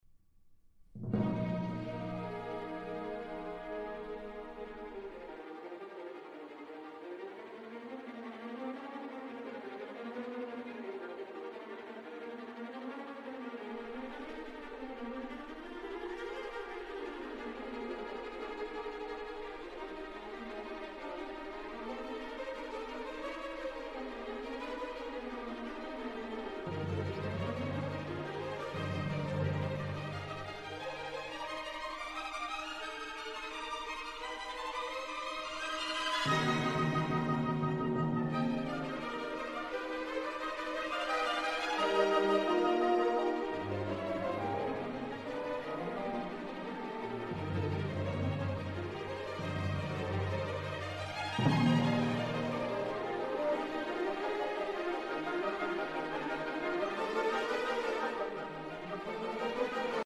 Jean Sibelius - Symphony No 5 In E Flat Major - Op 82 - 3. Allegro molto - Largamente assai
Allegro molto - Largamente assai - Great Classical Music